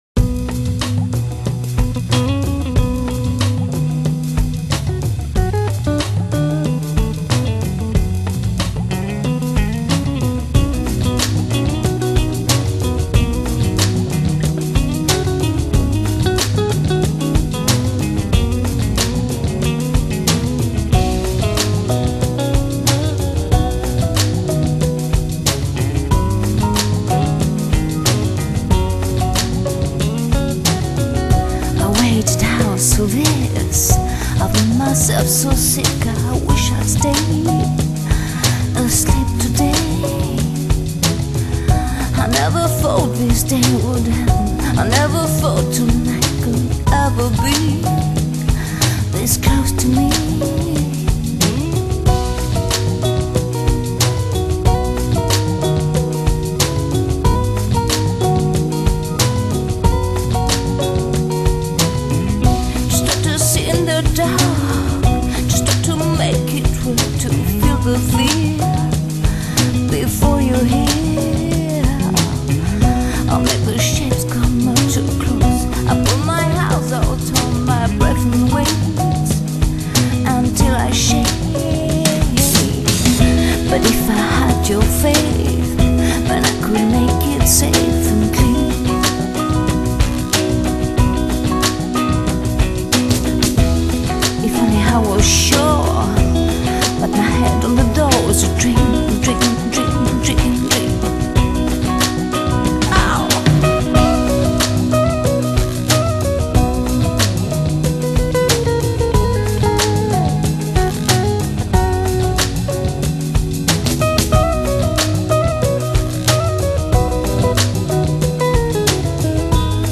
【唱片风格】流行
加入了R&B/爵士风格，为歌曲带来新的生命力。
在悠闲、会客、聊天时，完全抛弃了激烈节奏的金属和喧杂，给予一天紧张 工作后最自然的环境曲放松。